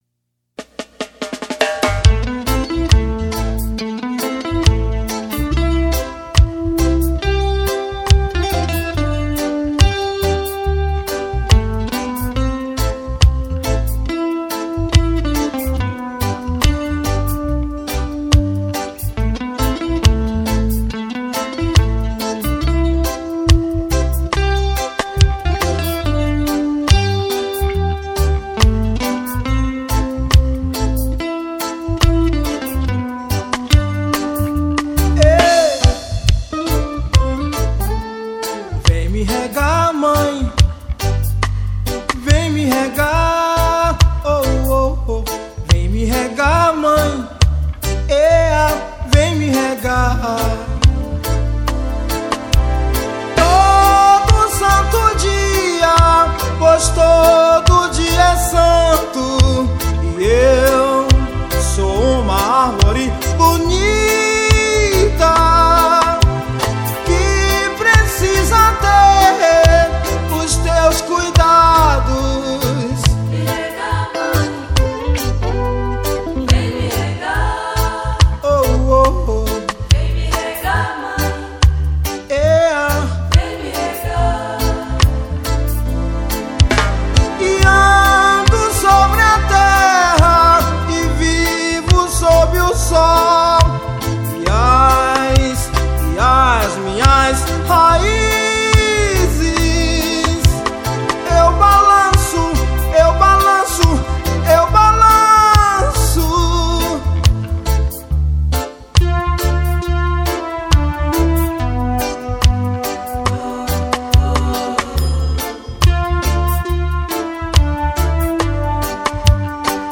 2024-02-14 20:03:14 Gênero: Reggae Views